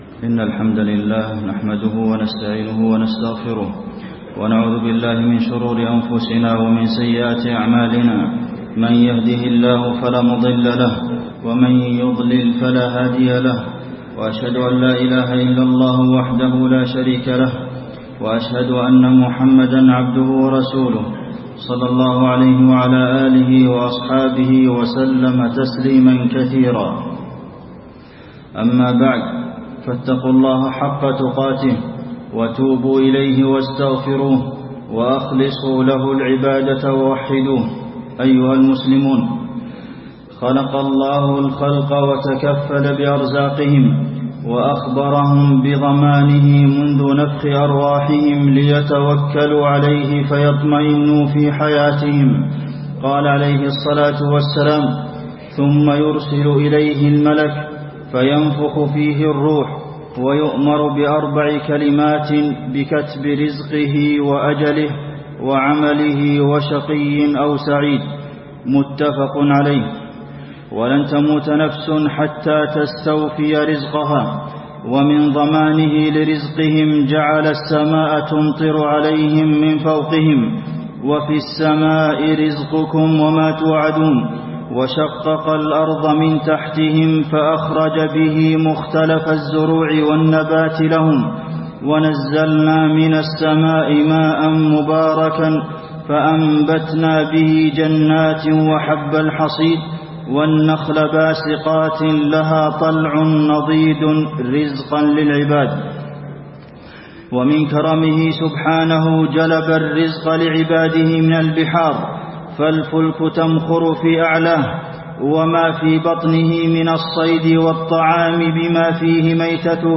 خطبة الاستسقاء - المدينة- الشيخ عبدالمحسن القاسم
المكان: المسجد النبوي